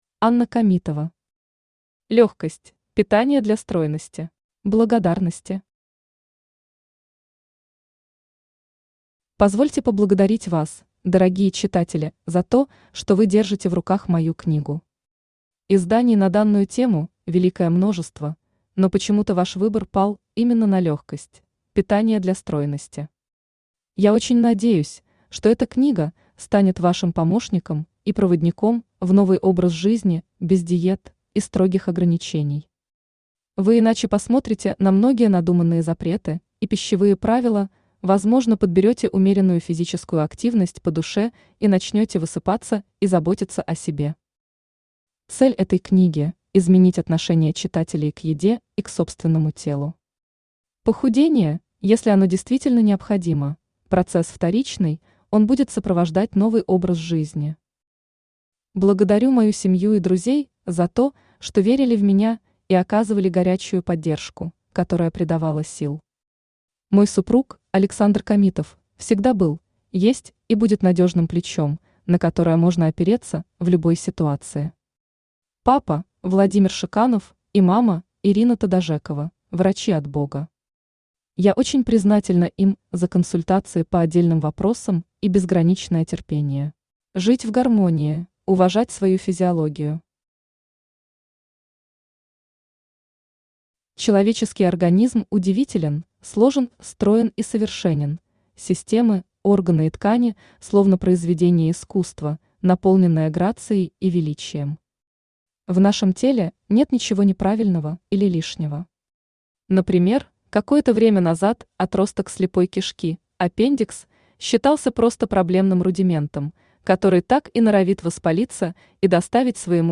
Аудиокнига Легкость: питание для стройности | Библиотека аудиокниг
Aудиокнига Легкость: питание для стройности Автор Анна Камитова Читает аудиокнигу Авточтец ЛитРес.